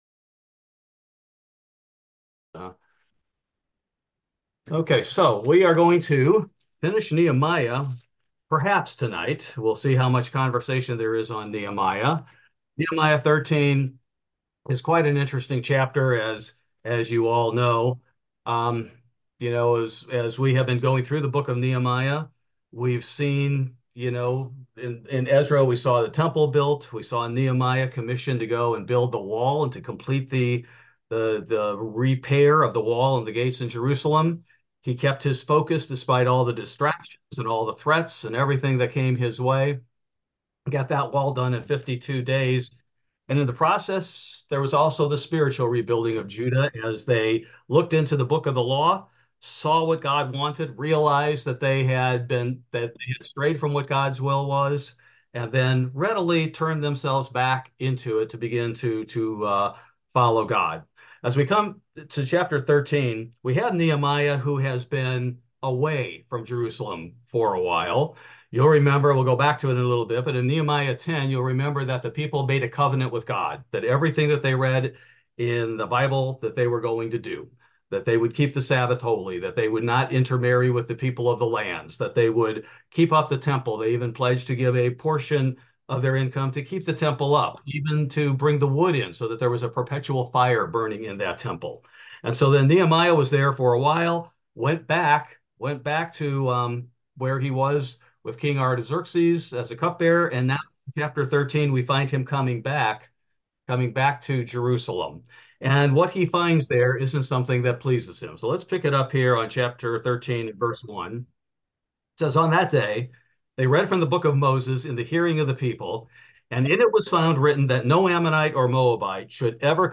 Bible Study: March 6, 2024
Nehemiah returns to Jerusalem to find the Jews have departed from God. He turns the people back to God. Bible study contains discussion among the attendees on the Sabbath portion of Nehemiah 13.